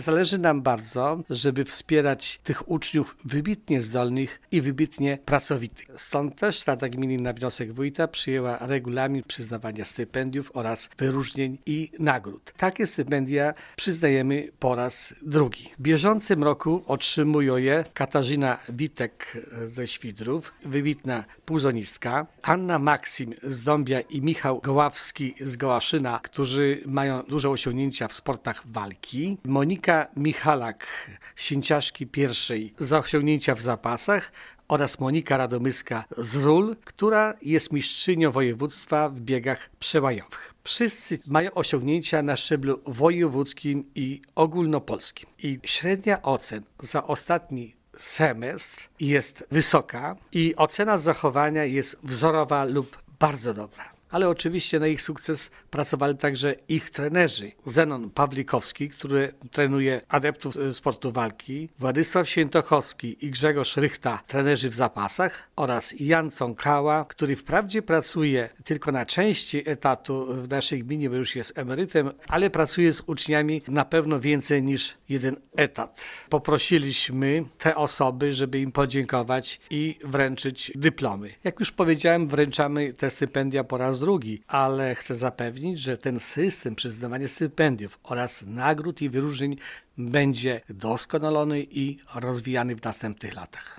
Zastępca wójta Wiktor Osik podkreśla, że gmina od wielu lat przywiązuje dużą wagę do poziomu edukacji.